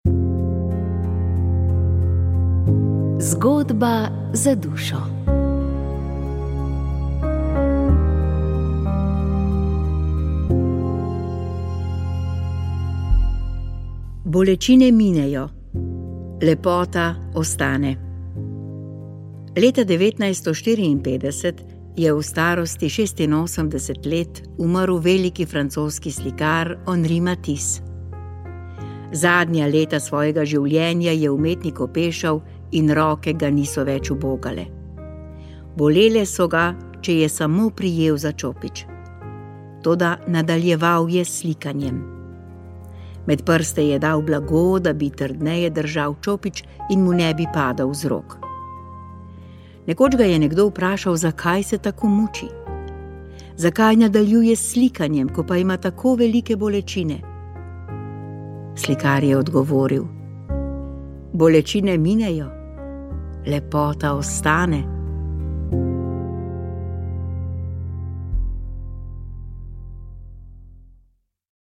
Molili so Molilci pobude Molitev in post za domovino